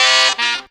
GROWL RIFF 1.wav